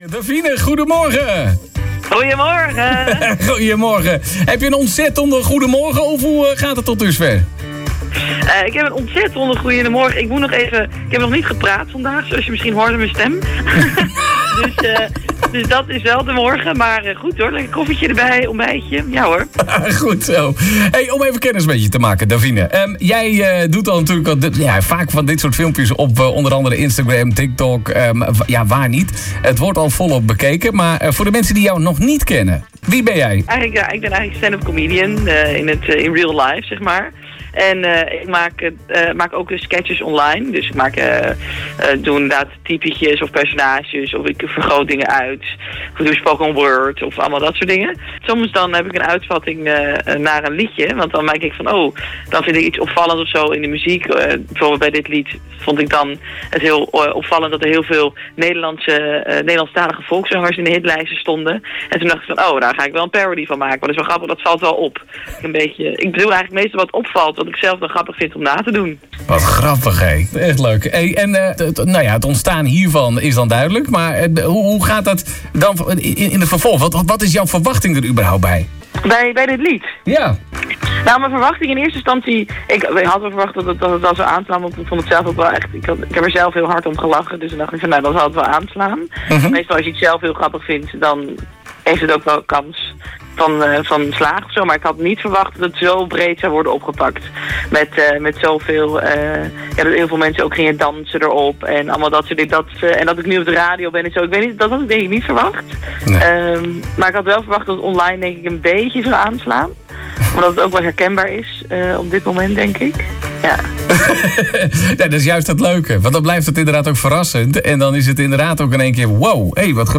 Het gesprek was open, spontaan en vol passie voor muziek.